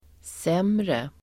Uttal: [s'em:re]